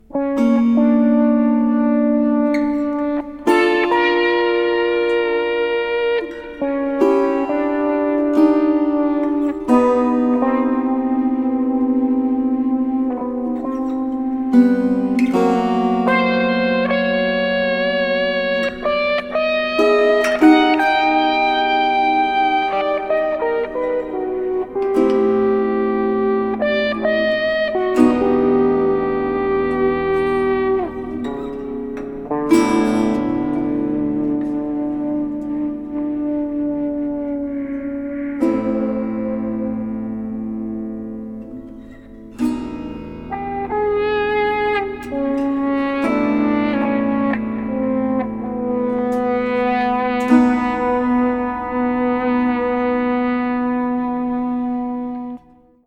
Electric guitar